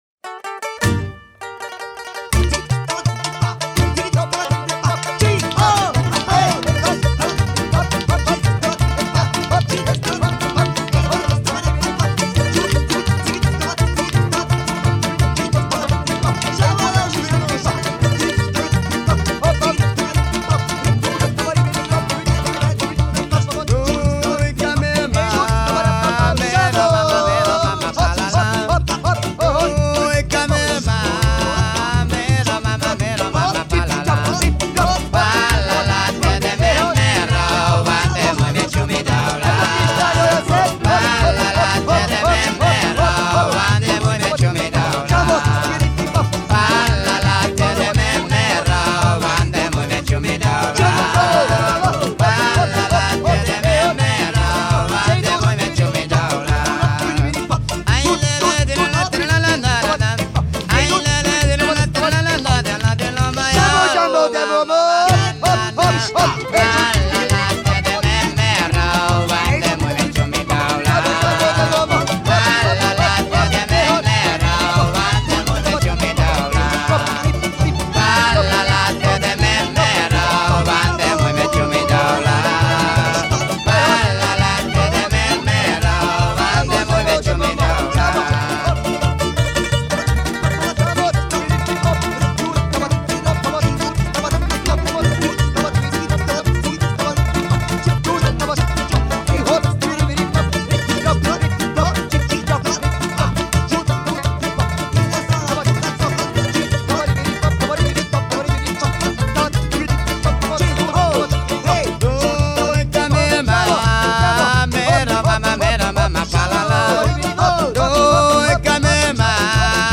Музыка народов мира